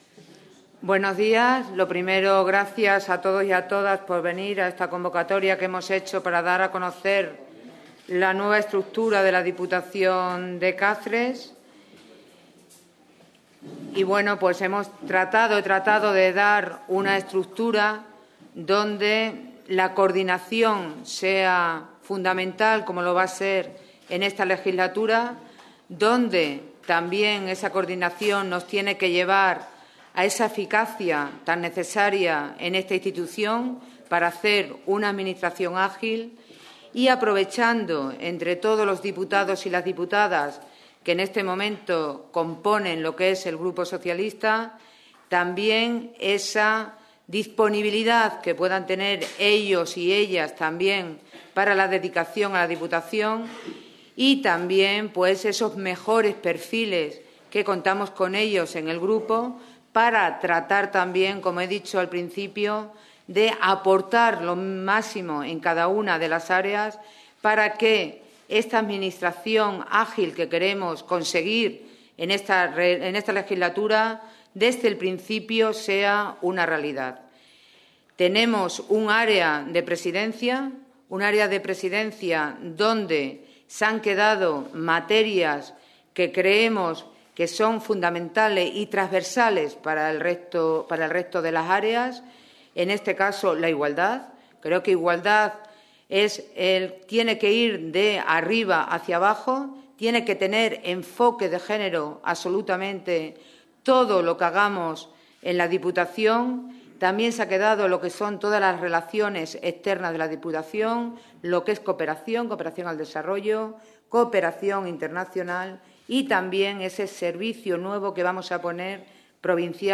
CORTES DE VOZ
La presidenta de la Diputación de Cáceres, Rosario Cordero, ha presentado este miércoles en rueda de prensa el nuevo equipo de gobierno de la Institución Provincial y ha dado a conocer los nombres de los diputados y diputadas que se encargarán de las diferentes áreas durante los próximos cuatro años.